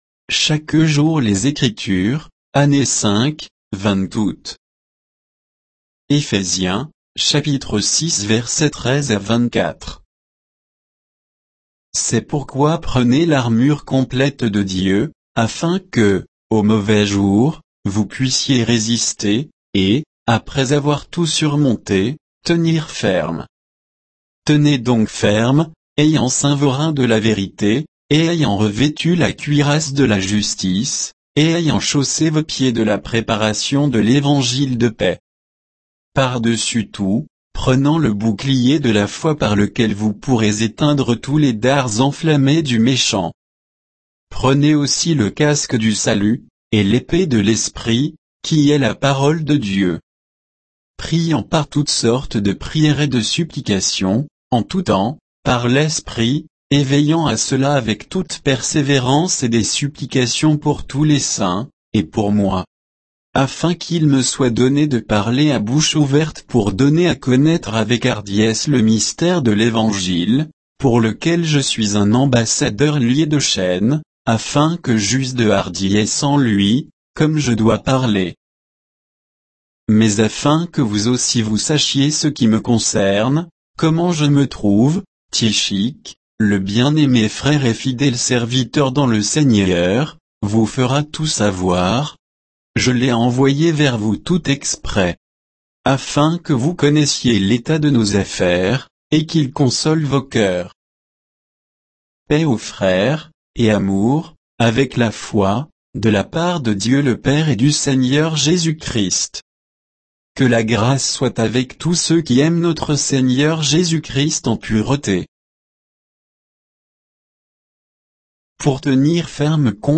Méditation quoditienne de Chaque jour les Écritures sur Éphésiens 6, 13 à 24